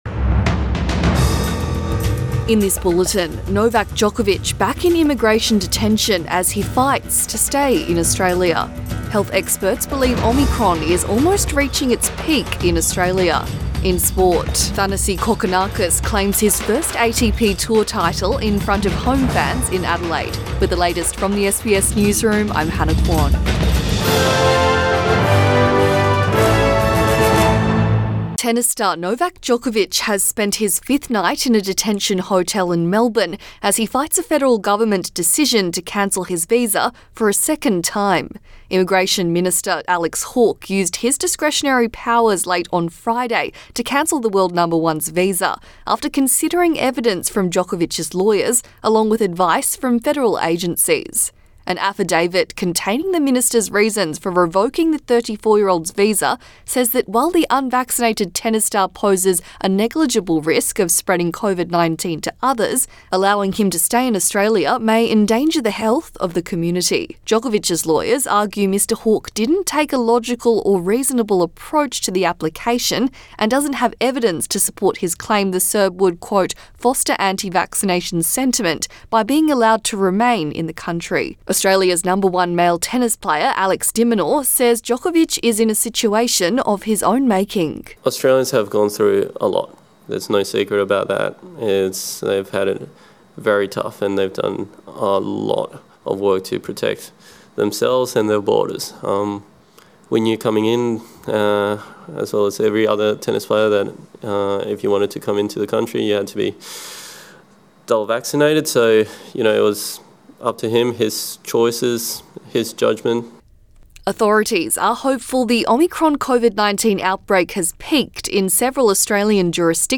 AM bulletin 16 January 2022